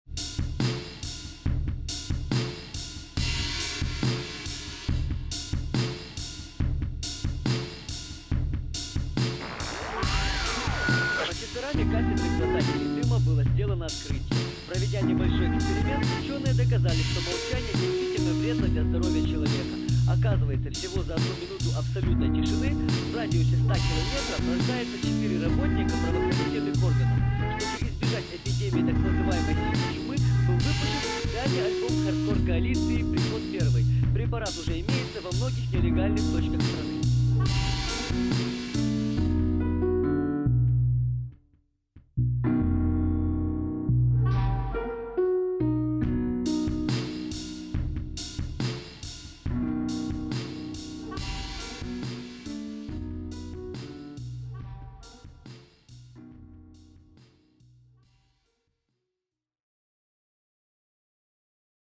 Genre             : Hardcore Rap
• Жанр: Рэп